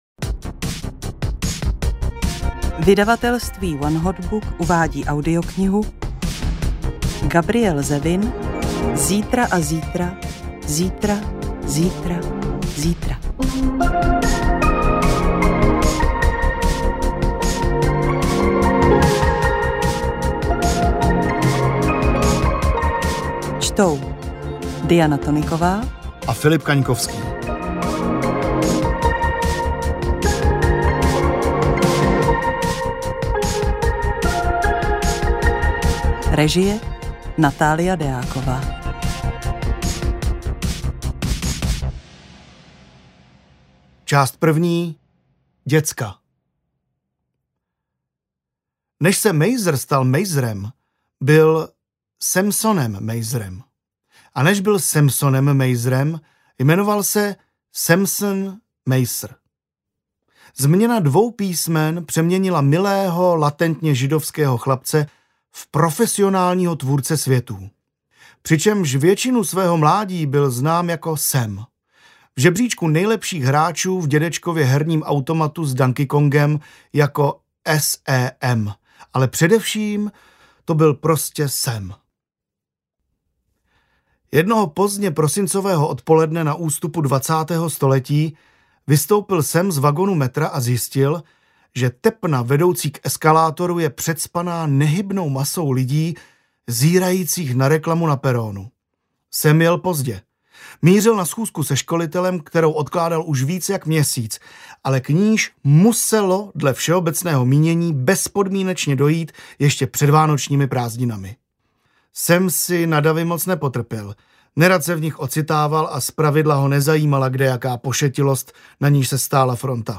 AudioKniha ke stažení, 52 x mp3, délka 16 hod. 31 min., velikost 900,0 MB, česky